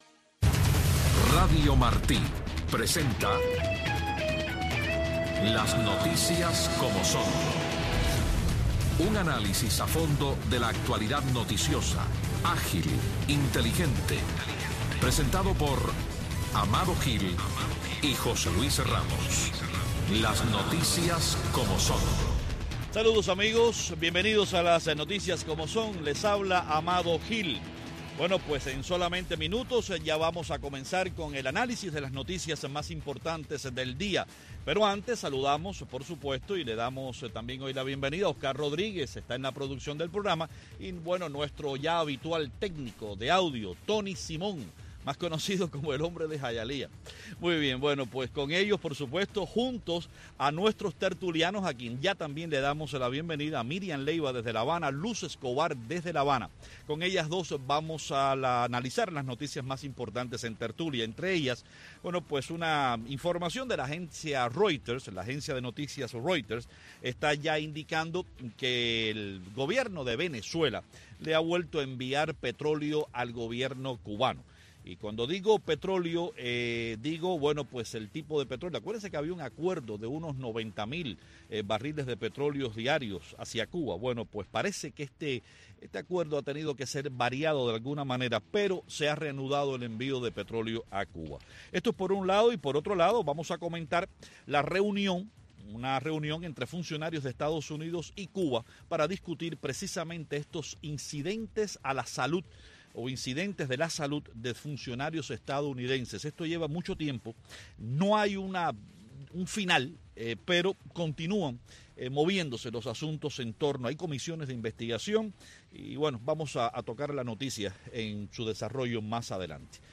Una discusión dinámica y a fondo de las principales noticias del acontecer diario de Cuba y el mundo, con la conducción de los periodistas